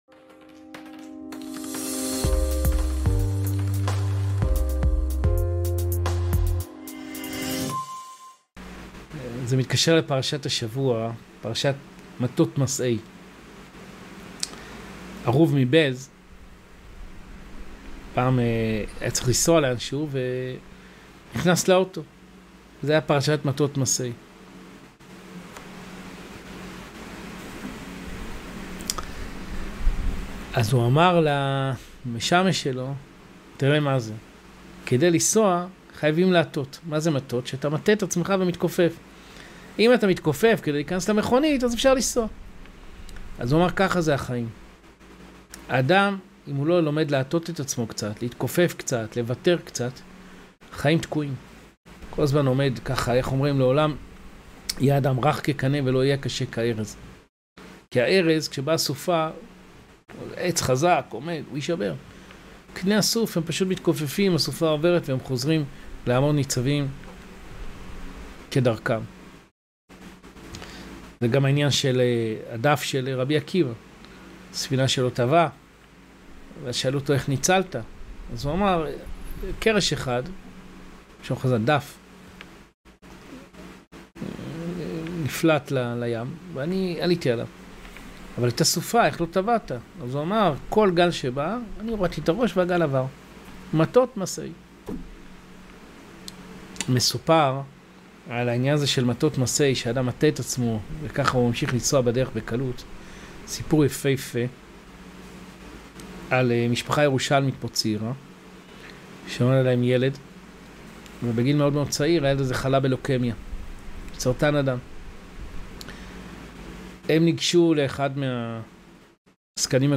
פרשת מטות מסעי | הדרך להתמודד עם אתגרי החיים | שיעור